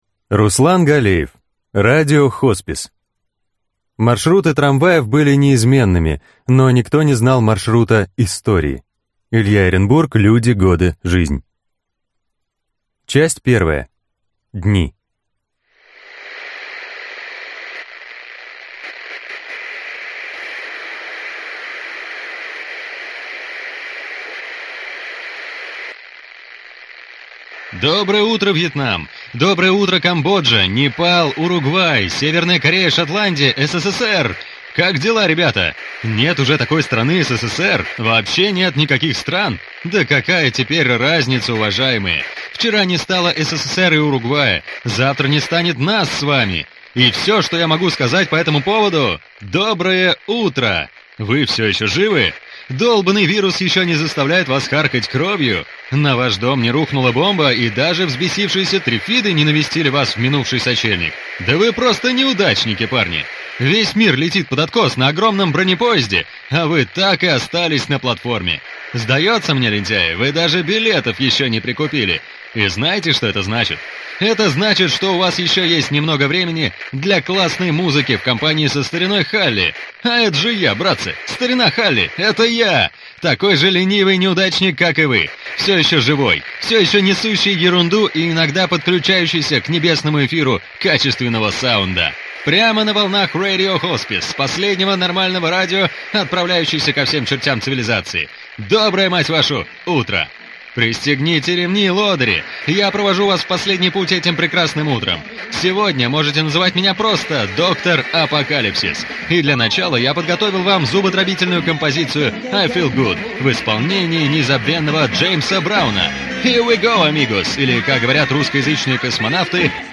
Аудиокнига Радио Хоспис | Библиотека аудиокниг